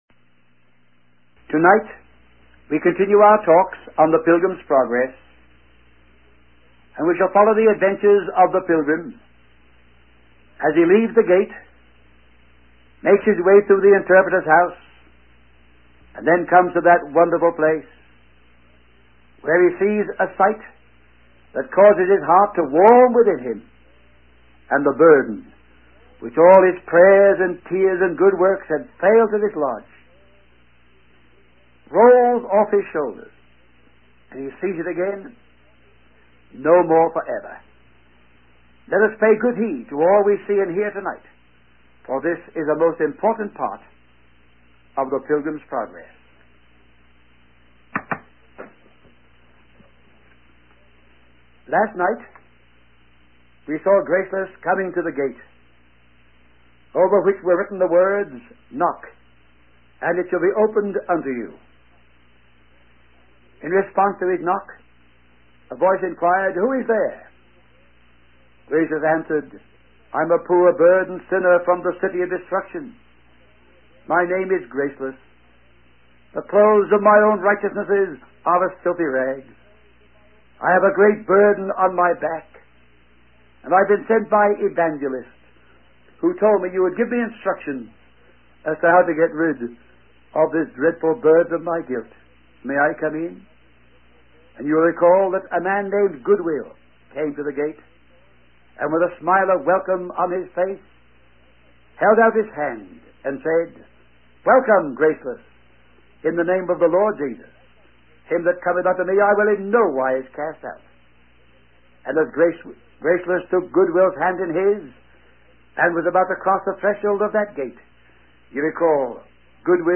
In this sermon, the preacher discusses the importance of having patience and putting faith and trust in the Lord Jesus. He emphasizes that salvation comes through God's grace and that believers are kept by God's power, enjoying spiritual pleasures as children of God.